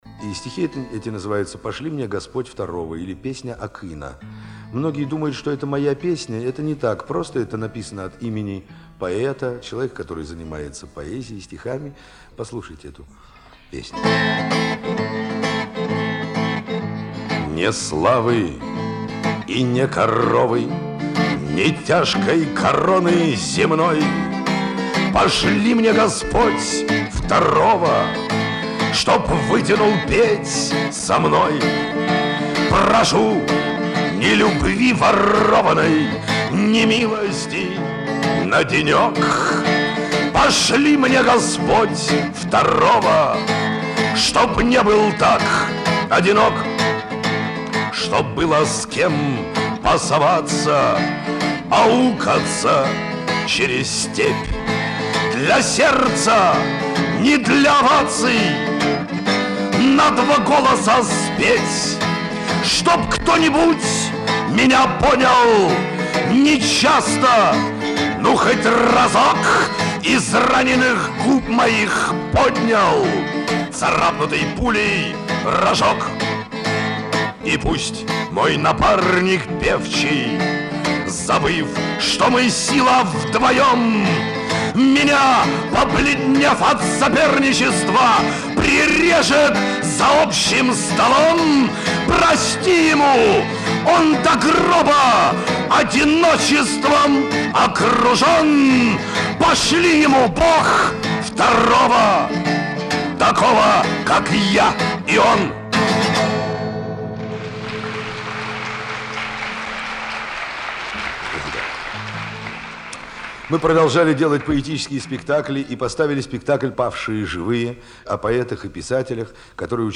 фрагмент концерта